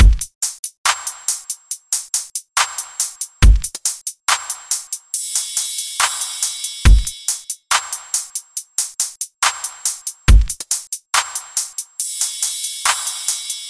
标签： 140 bpm Trap Loops Bells Loops 1.15 MB wav Key : C FL Studio
声道立体声